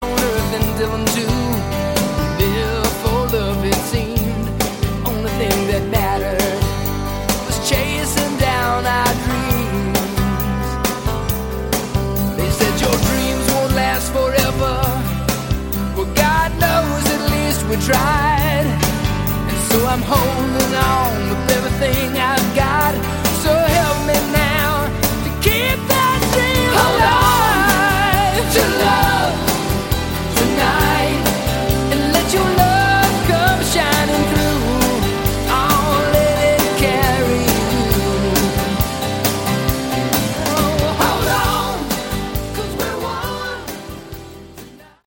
Category: West Coast
Guitars
Vocals, Bass
Vocals, Drums
Keyboards
Hammond B3
Backing Vocals